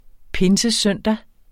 pinsesøndag substantiv, fælleskøn Bøjning -en, -e, -ene Udtale [ ˈpensəˈsœnˀda ] Betydninger søndagen og den vigtigste helligdag i pinsen Synonym pinsedag På søndag er det pinsesøndag.